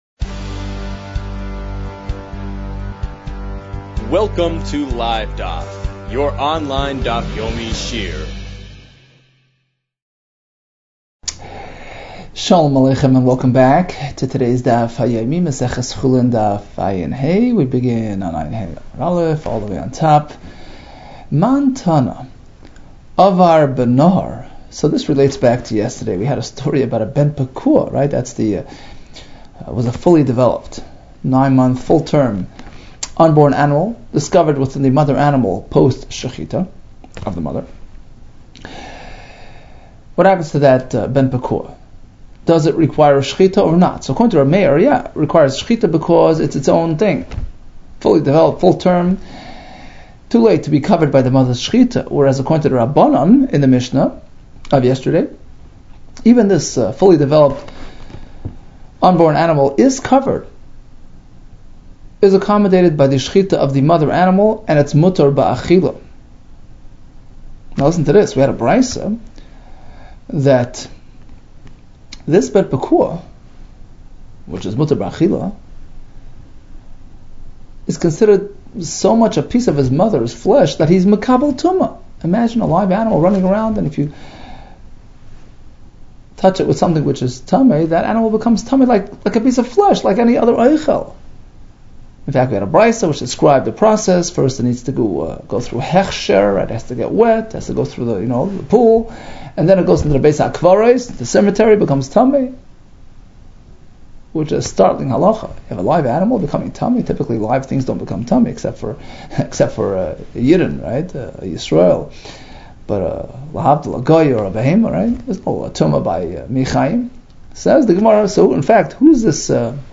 Chulin 75 - חולין עה | Daf Yomi Online Shiur | Livedaf